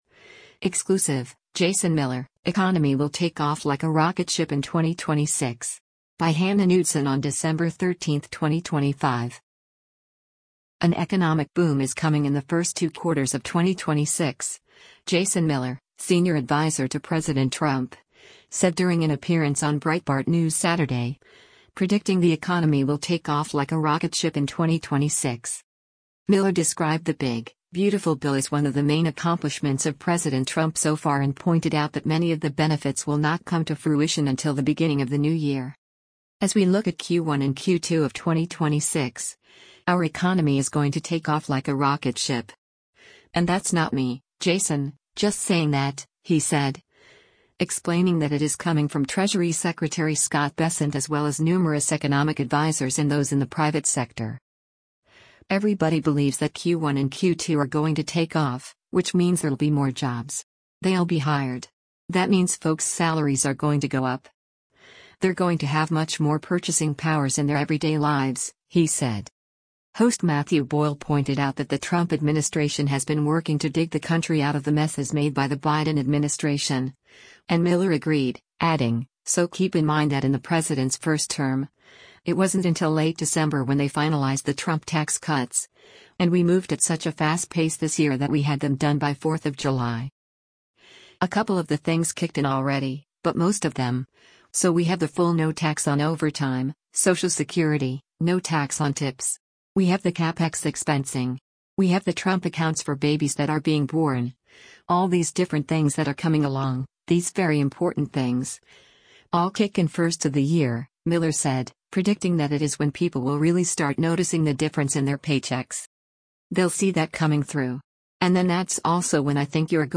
An economic boom is coming in the first two quarters of 2026, Jason Miller, senior advisor to President Trump, said during an appearance on Breitbart News Saturday, predicting the economy will “take off like a rocket ship” in 2026.